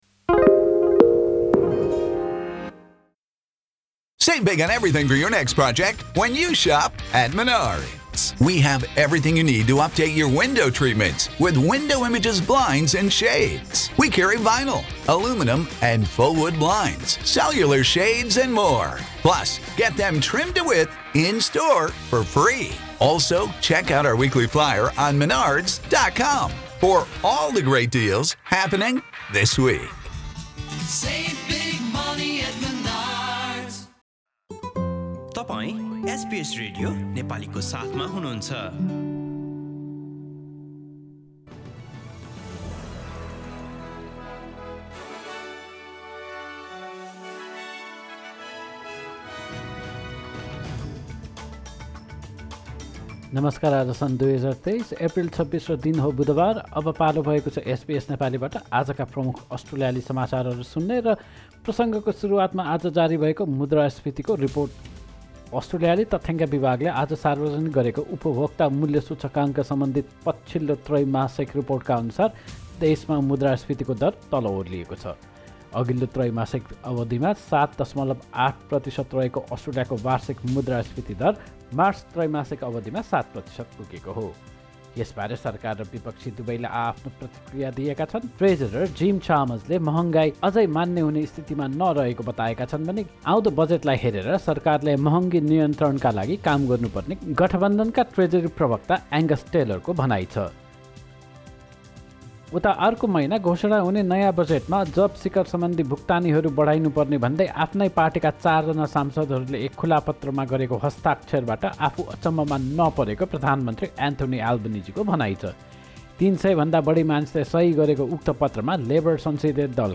एसबीएस नेपाली प्रमुख अस्ट्रेलियाली समाचार : बुधवार, २६ एप्रिल २०२३